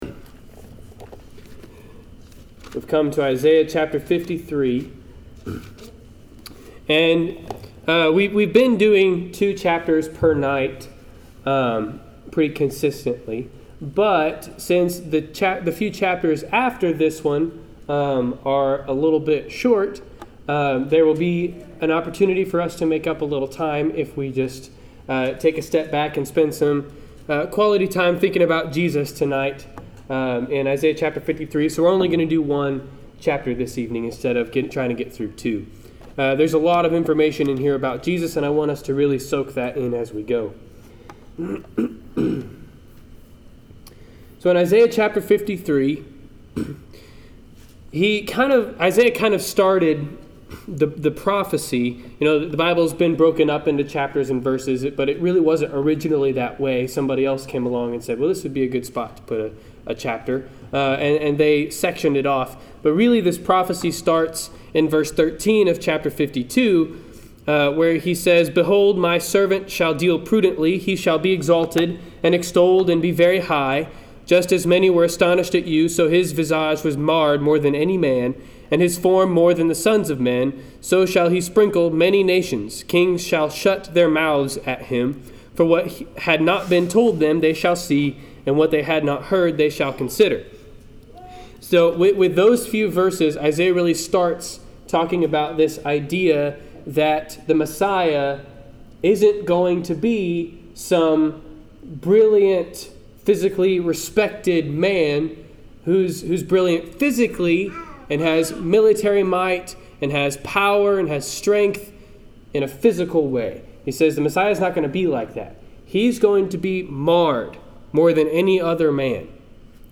Isaiah 53 Service Type: Wednesday Night Class Download Files Notes Notes Notes Topics